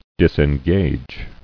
[dis·en·gage]